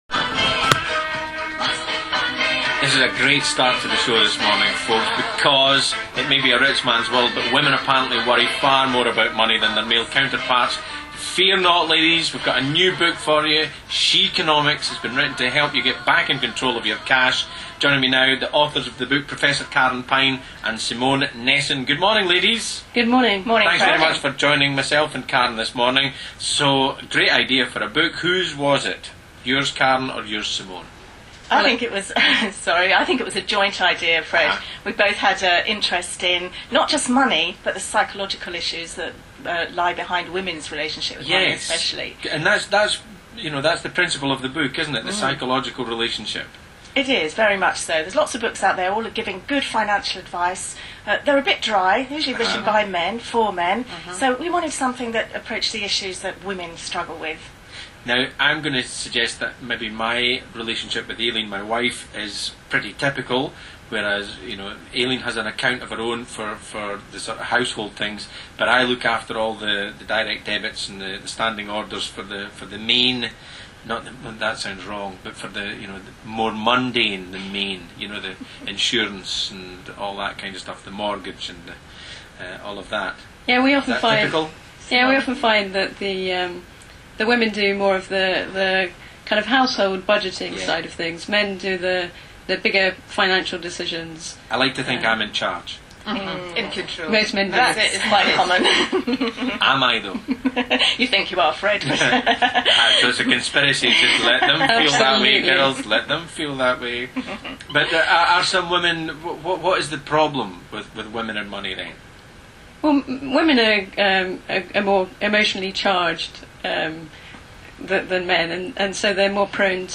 radio_scotland.WMA